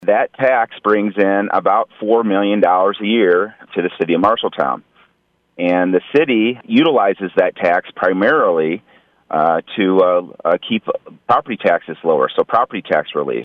Chief Tupper then goes on to explains how LOST, Local Option Sales Tax, works in our community